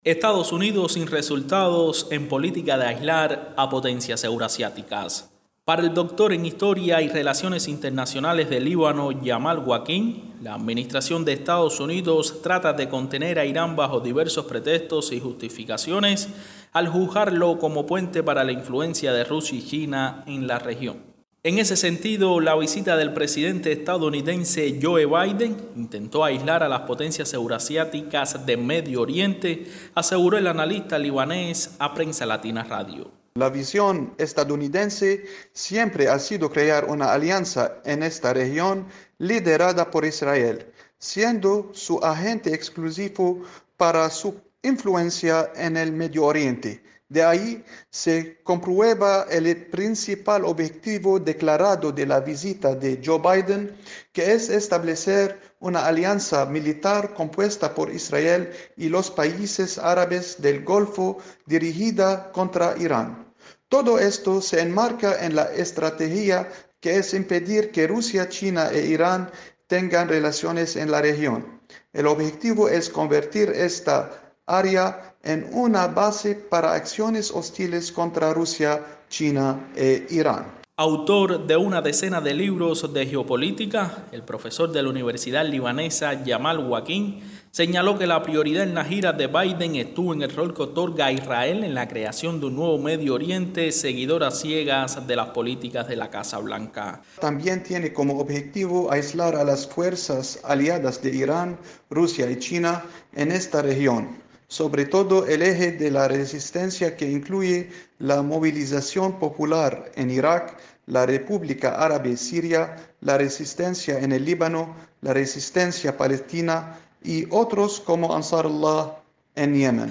desde Beirut